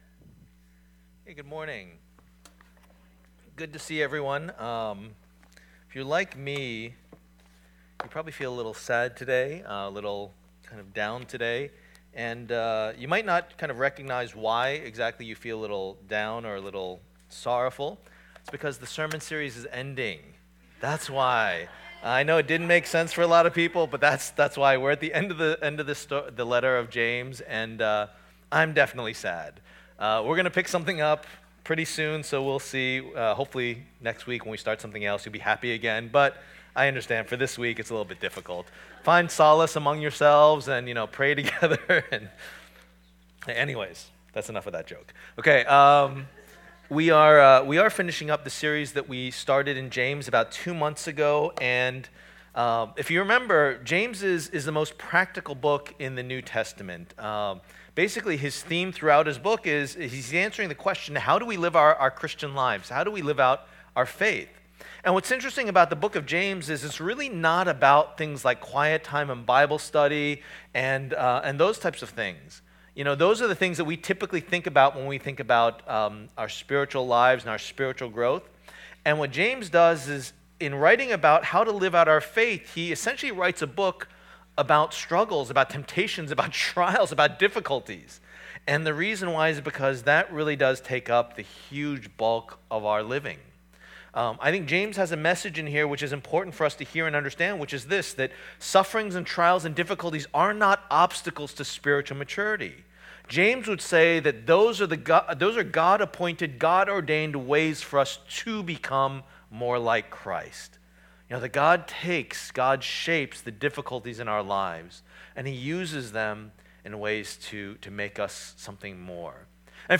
Passage: James 5:13-20 Service Type: Lord's Day